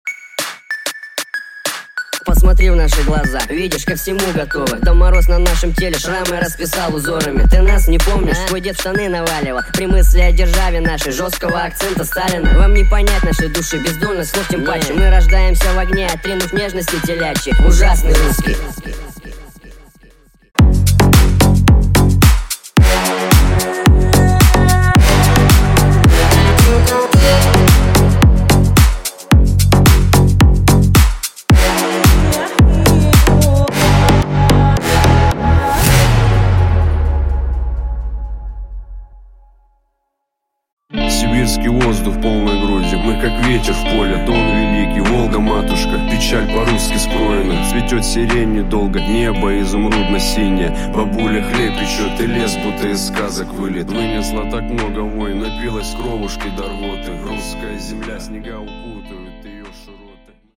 • Качество: 128, Stereo
мужской голос
русский рэп
клубняк
Mashup
цикличные
Нарезка и парочки рэп треков и одного клубняка